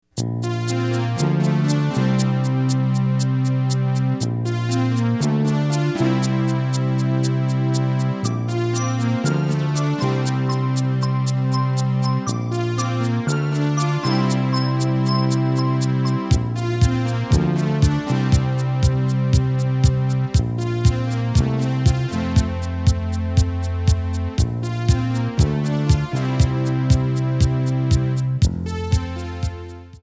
Backing track files: All (9793)
Audio Backing tracks in archive: 9793